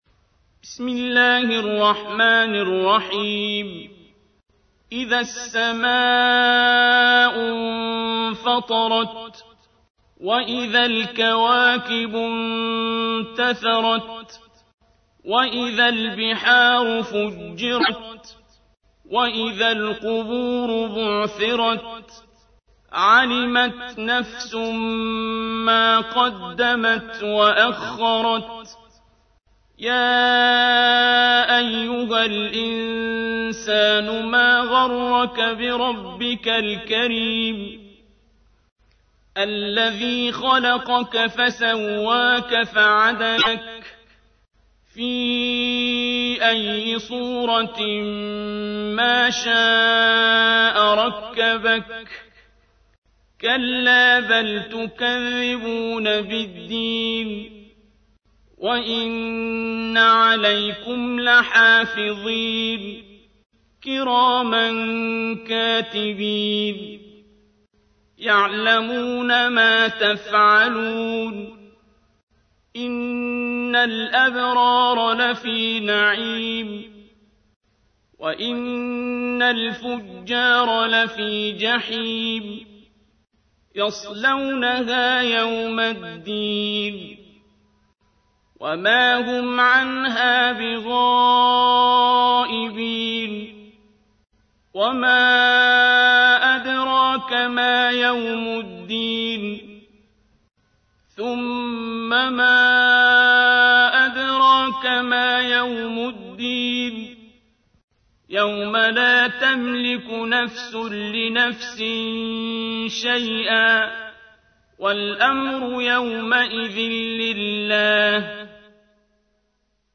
تحميل : 82. سورة الانفطار / القارئ عبد الباسط عبد الصمد / القرآن الكريم / موقع يا حسين